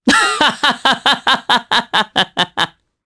Roi-Vox_Happy3_jp.wav